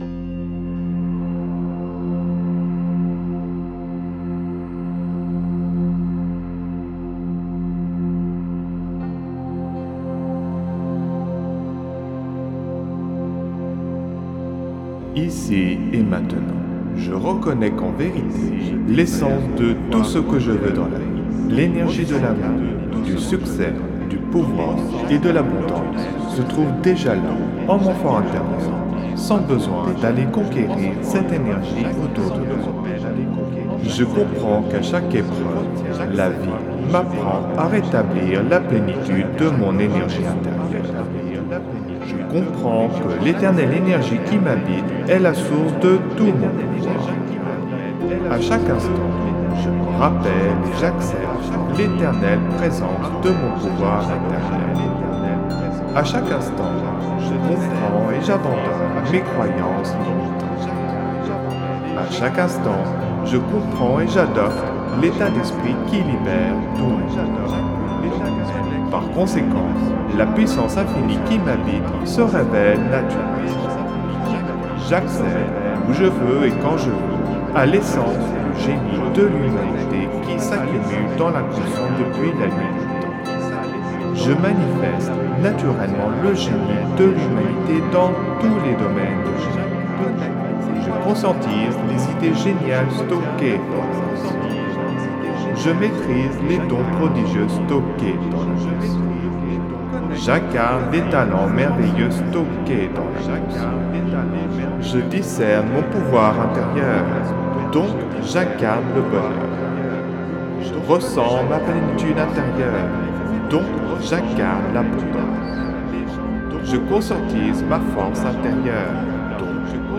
(Version ÉCHO-GUIDÉE)
Alliage ingénieux de sons et fréquences curatives, très bénéfiques pour le cerveau.
Pures ondes gamma intenses 87,02 Hz de qualité supérieure. Puissant effet 3D subliminal écho-guidé.
SAMPLE-Guru-social-3-echo.mp3